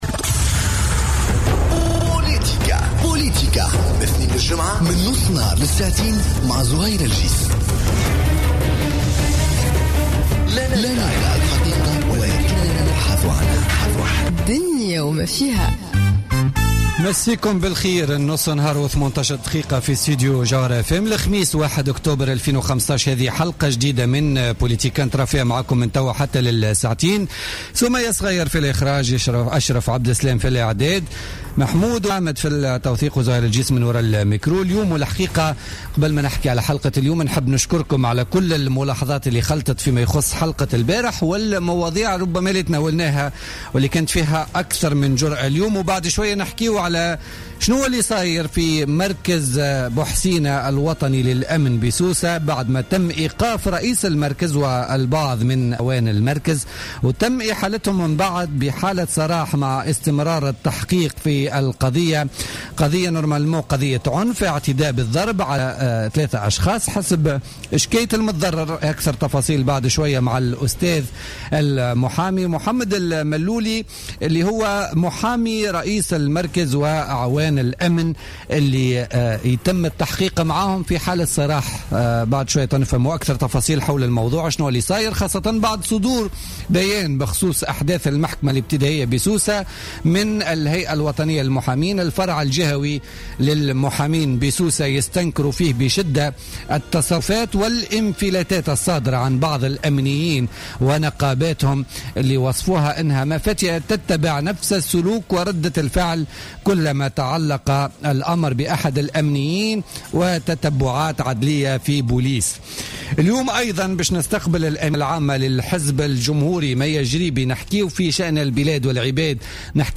Interview avec Maya Jribi